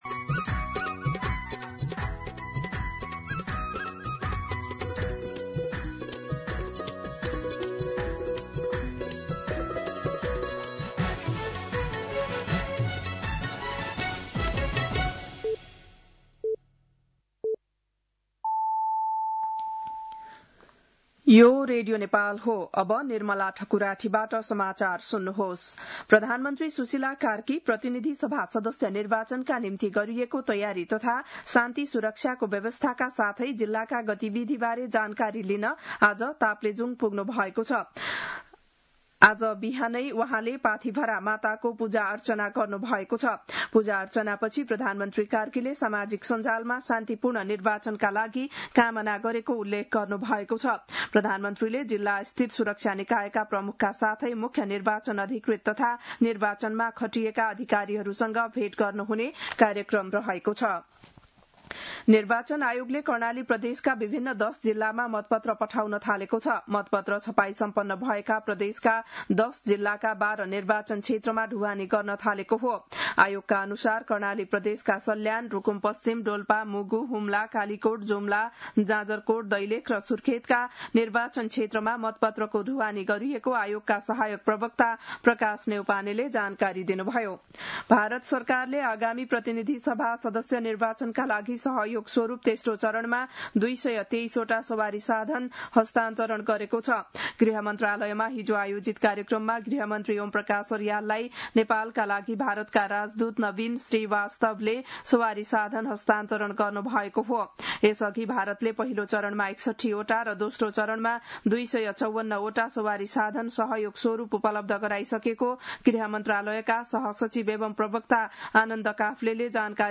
बिहान ११ बजेको नेपाली समाचार : १ फागुन , २०८२